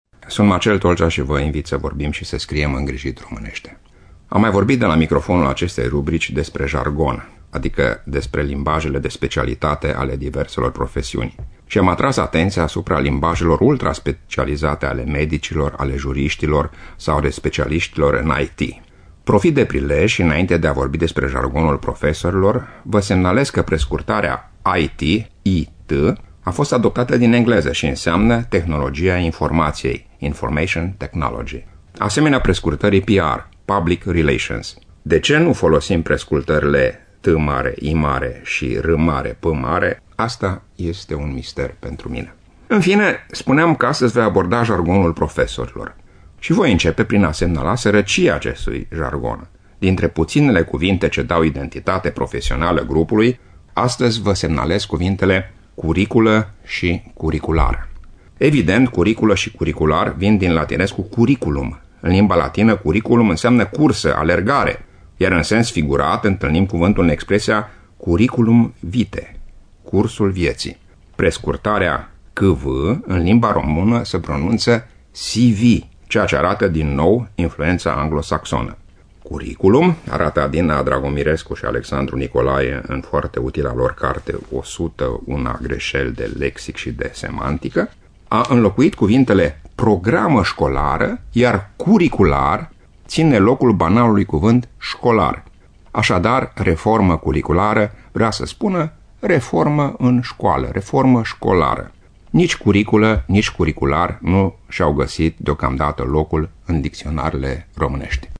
Rubricile sunt difuzate de luni până vineri inclusiv, în jurul orelor 7.40 şi 11.20 şi în reluare duminica, de la ora 8.00.